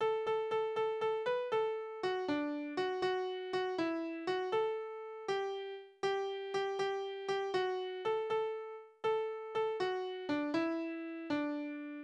« O-6057 » Runkeldekunkel de Wog' Wiegenlieder: Runkeldekunkel de Wog' schmitt um, de Perkes sind erdrunke, Ach wo weint de Reiters-mann, och ner schimpt de Jun-ker. Tonart: D-Dur Taktart: 6/8 Tonumfang: Quinte Besetzung: vokal Externe Links: Sprache: niederdeutsch eingesendet von unbekannt (o. D.)